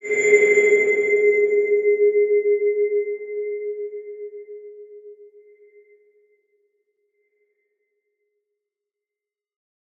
X_BasicBells-G#2-mf.wav